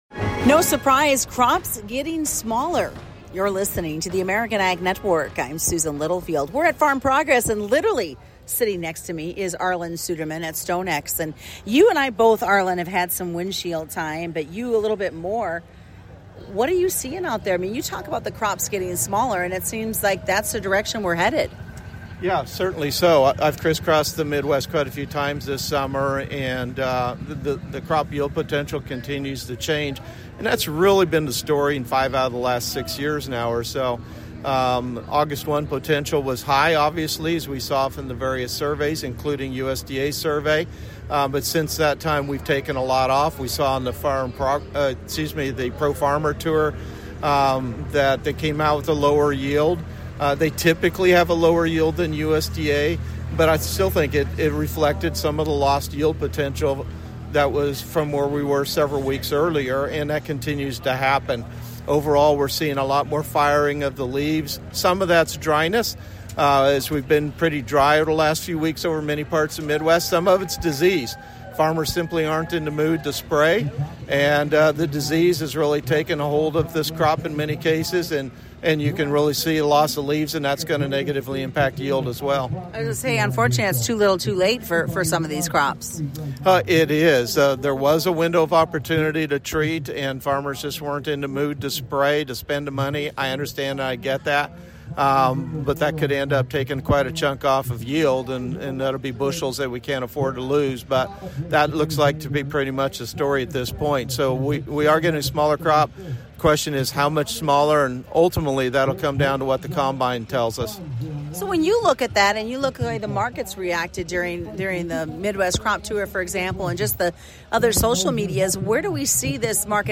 from the Farm Progress Show…